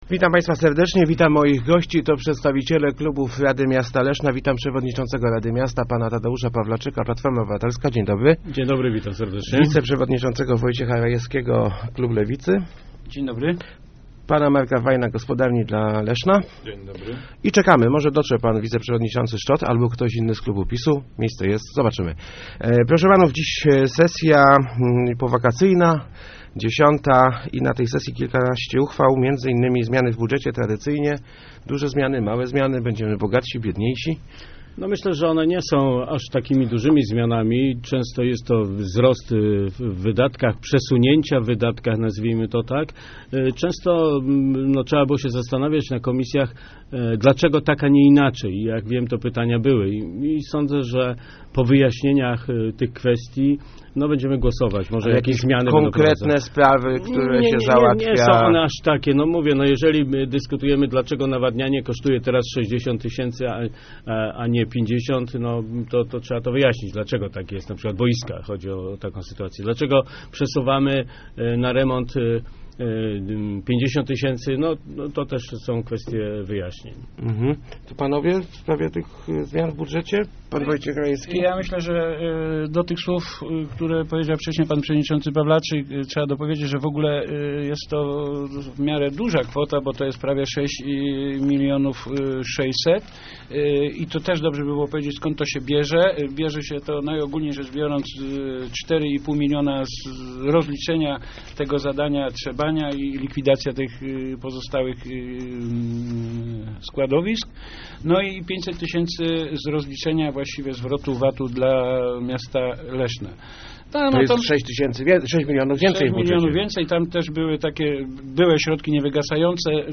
Chcemy, żeby najmniej zarabiający pracownicy samorządu dostali podwyżki - mówili w Rozmowach Elki Tadeusz Pawlaczyk z PO i Sławomir Szczot z PiS. Uchwała w tej sprawie głosowana będzie podczas czwartkowej sesji rady Miasta Leszna.